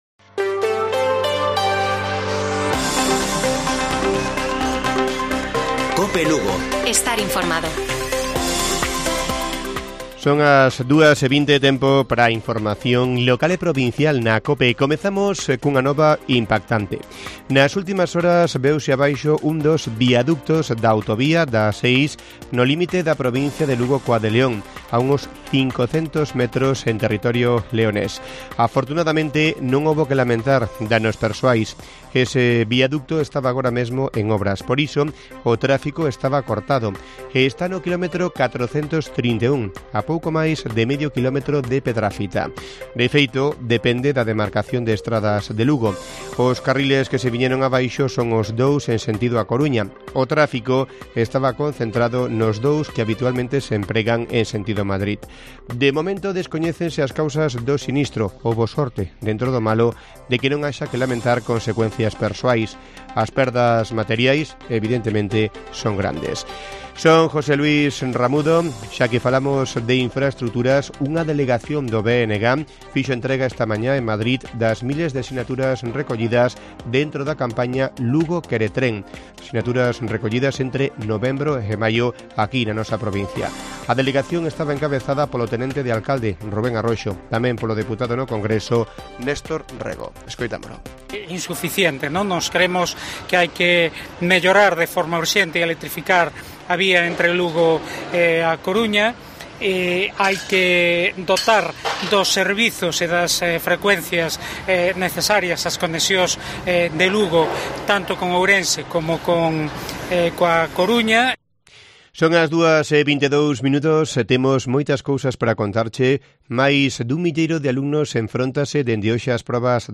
Informativo Mediodía de Cope Lugo. 07 DE JUNIO. 14:20 horas